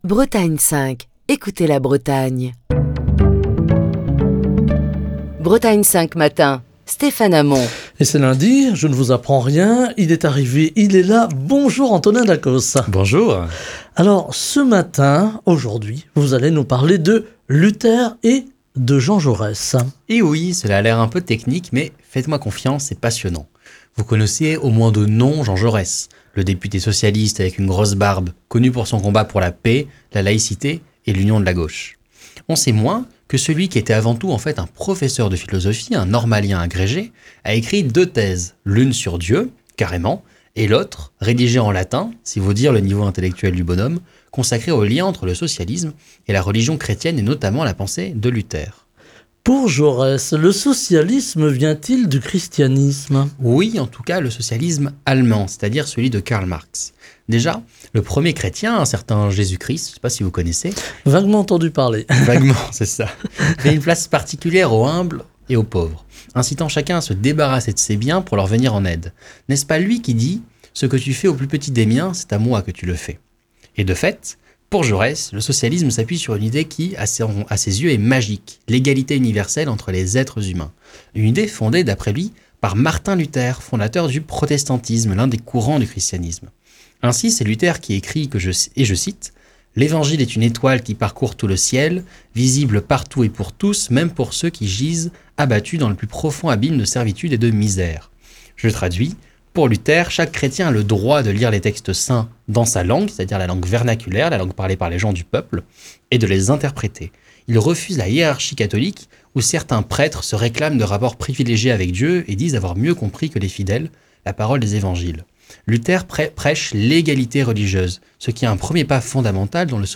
Chronique du 27 février 2023.